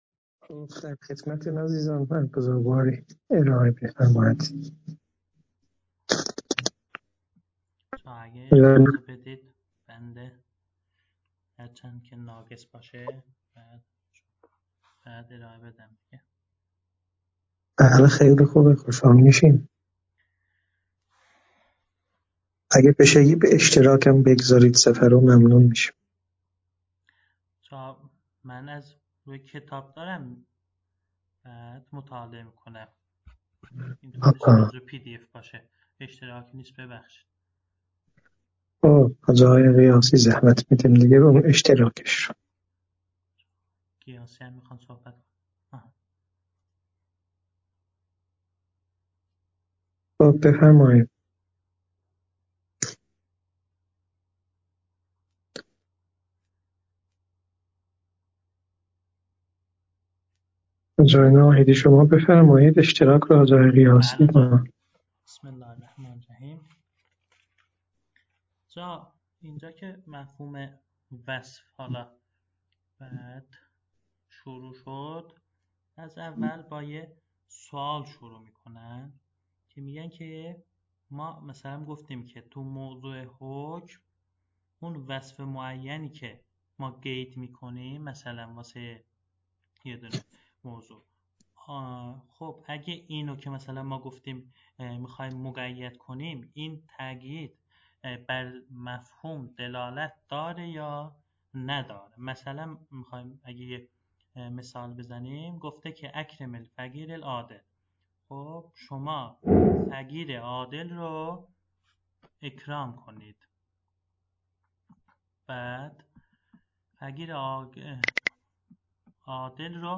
فایل های مربوط به تدریس كتاب حلقه ثانیه